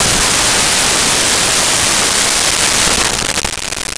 Auto Crash 1
Auto Crash_1.wav